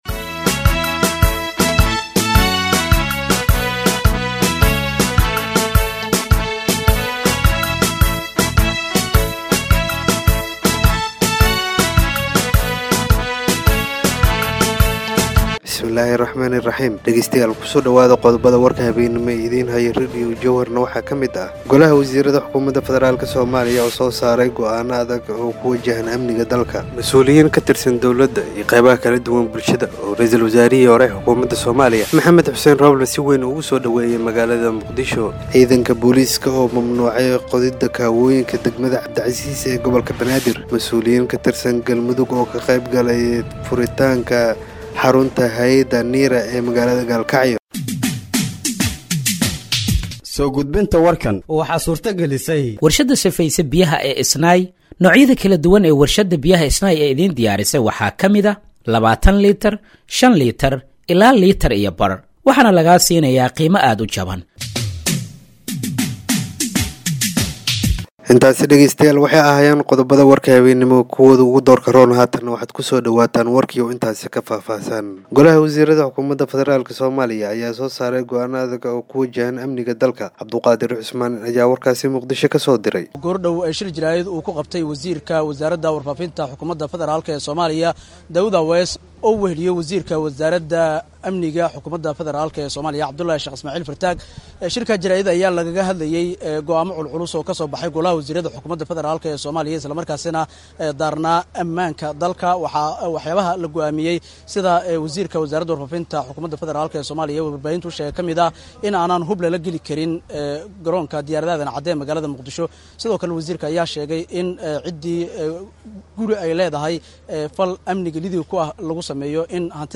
Dhageeyso Warka Habeenimo ee Radiojowhar 05/03/2025
Halkaan Hoose ka Dhageeyso Warka Habeenimo ee Radiojowhar